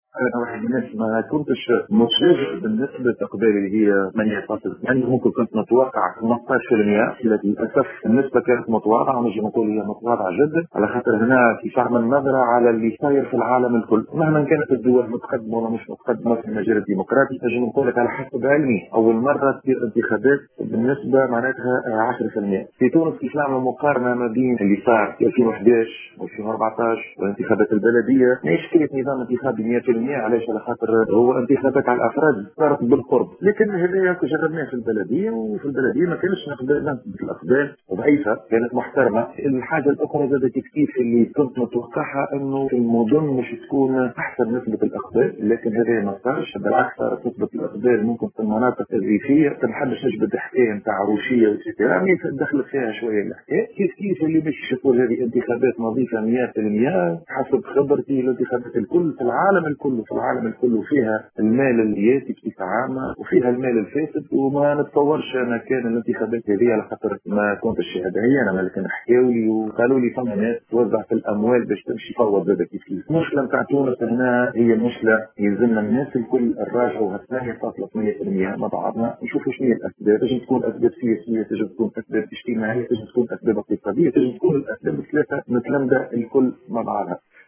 L’ancien membre de l’ISIE Anis Jarbouï a déclaré aujourd’hui au micro de Tunisie Numérique qu’il ne s’attendait pas à ce taux de participation aux élections législatives.